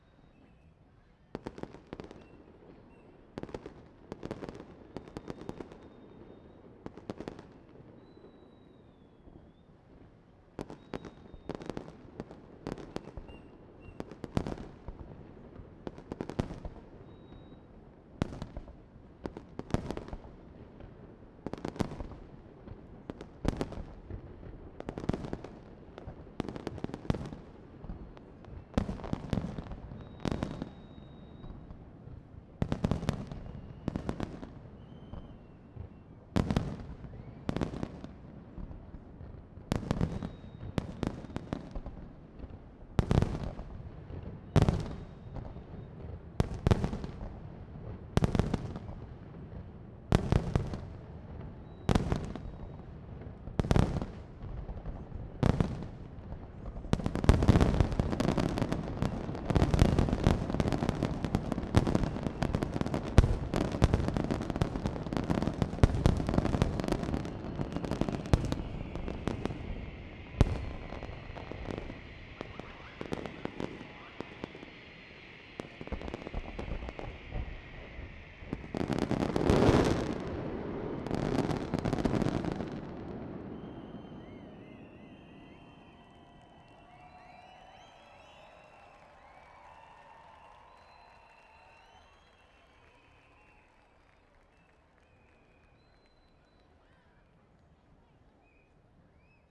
fireworks_ambiX.wav